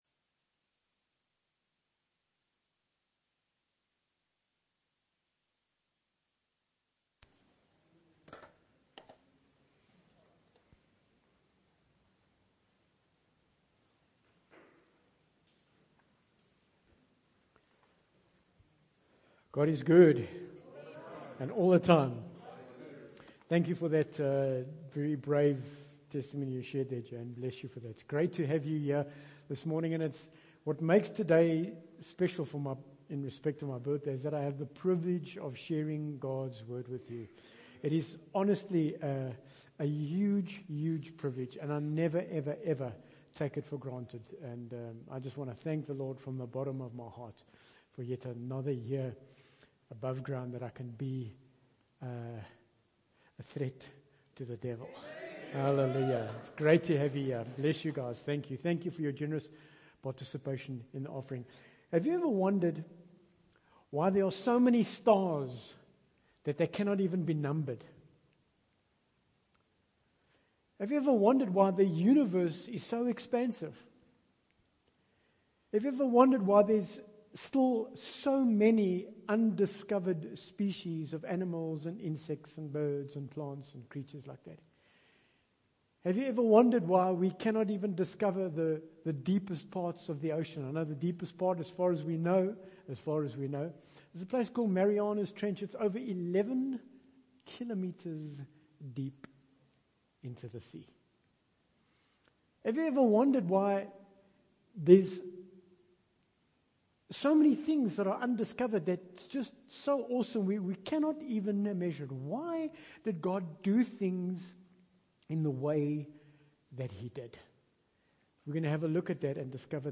Bible Text: Ephesians 3:20 | Preacher